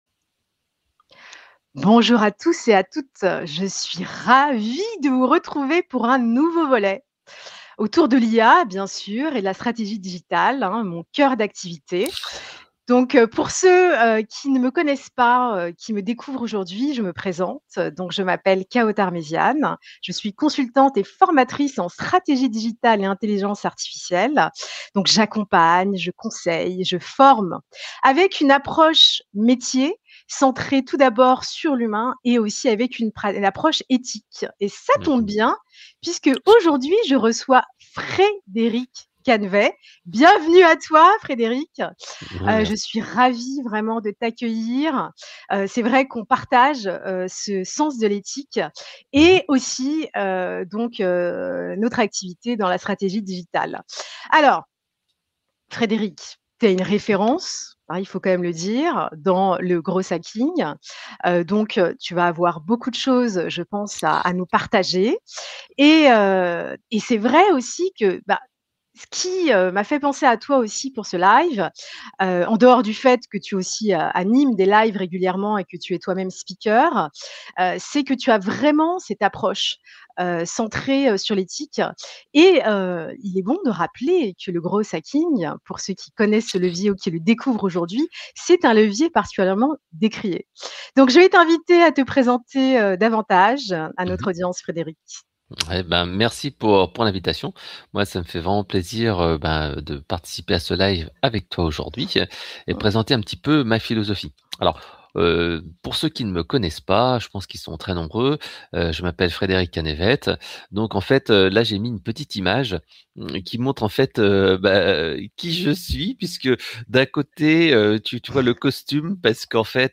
Dans le live, j’ai expliqué pourquoi le growth hacking est devenu une compétence centrale dans un monde BANI ( Fragile, Anxieux, Non-linéaire et Incompréhensible), où il faudra se remettre en cause régulièrement.